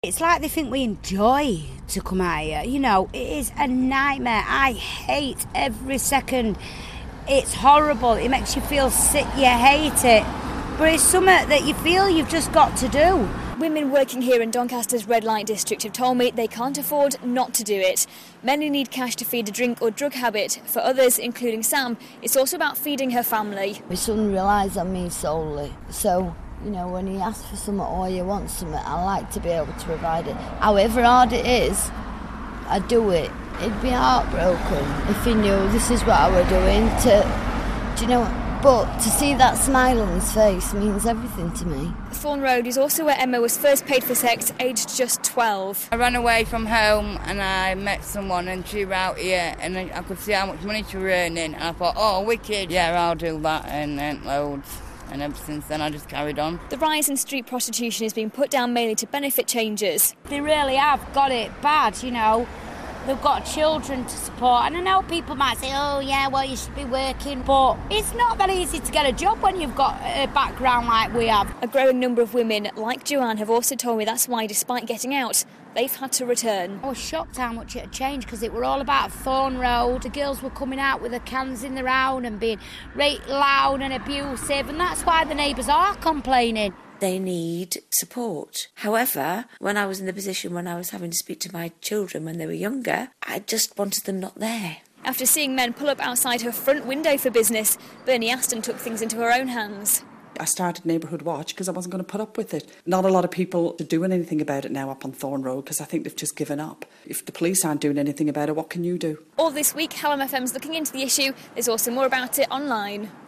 Hallam FM's been speaking to women who work and live around Thorne road in the red light district of Doncaster. New figures from the council show that there's been a 61% rise in the number of reports about the issue over the last year.